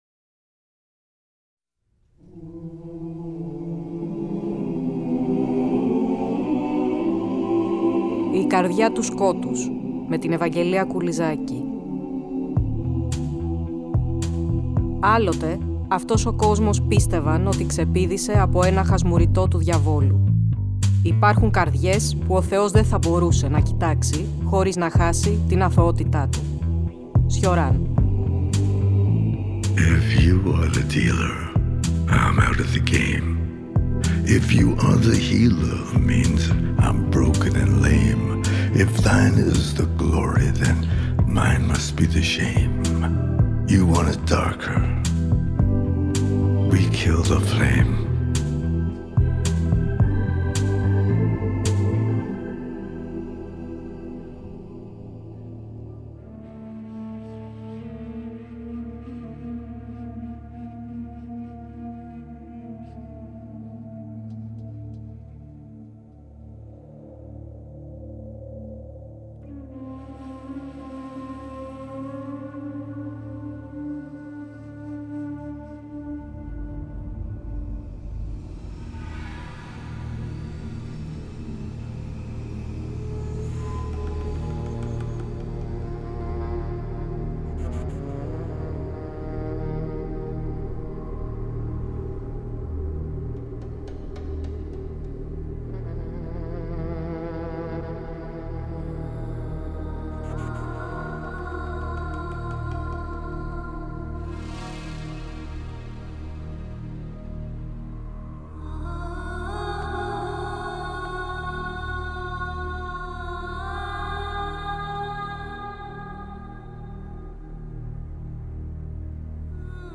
Στην εκπομπή ακολουθούμε τα ίχνη τους, με την αντίστοιχη μουσική επένδυση.